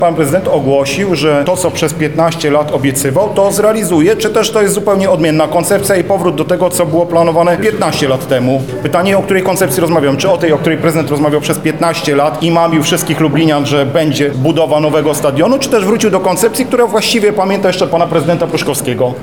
dr Robert Derewenda – komentuje Przewodniczący Klubu Radnych Prawa i Sprawiedliwości, dr Robert Derewenda.